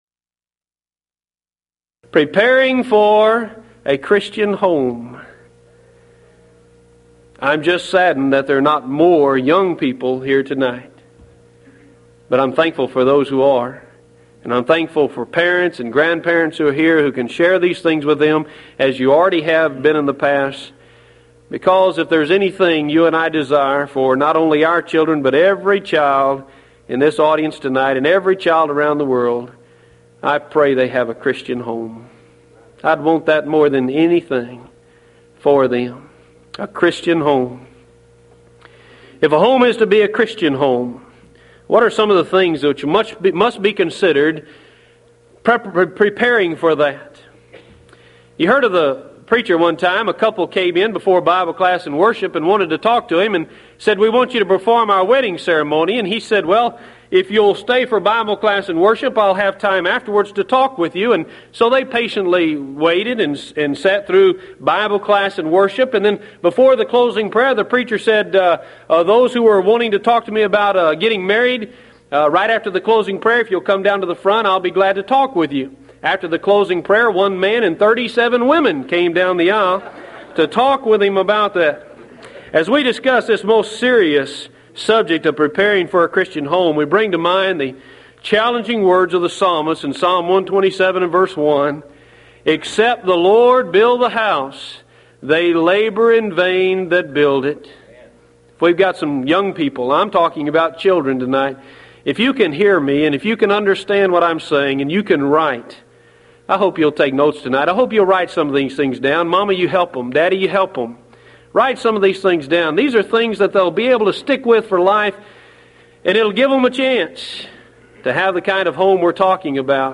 Event: 1993 Mid-West Lectures Theme/Title: The Christian Family